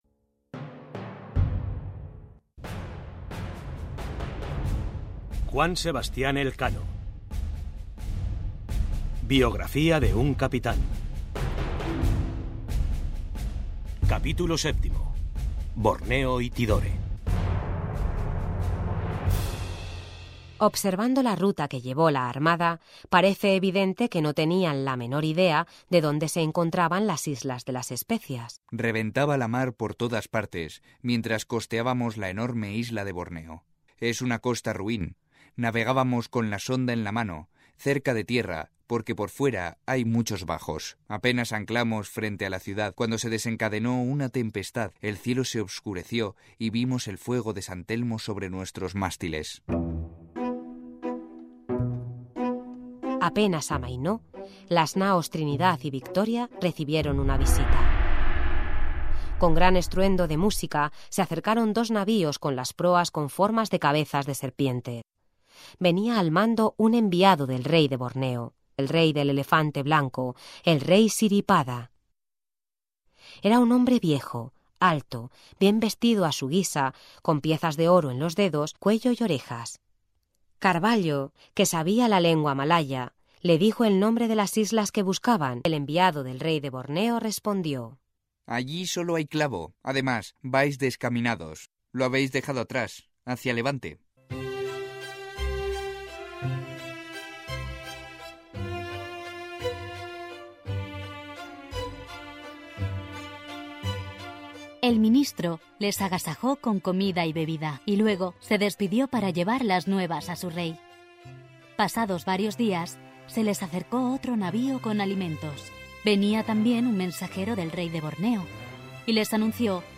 Audiolibro: Elkano biografía de un cápitan capítulo 7